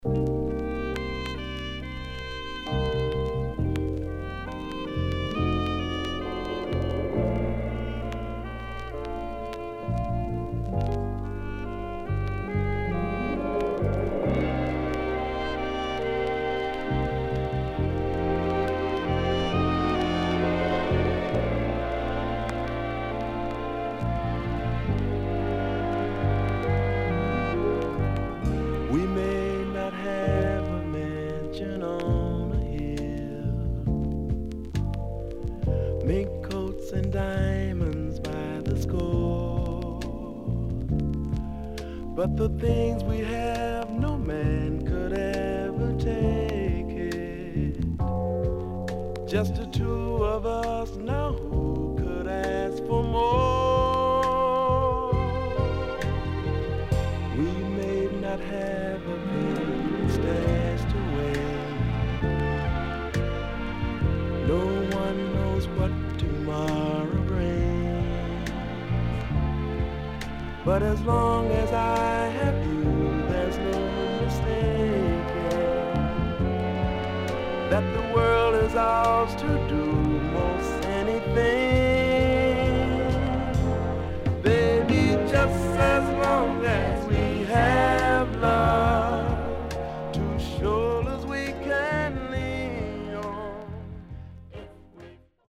HOME > SOUL / OTHERS
SIDE A:少しチリノイズ、プチパチノイズ入ります。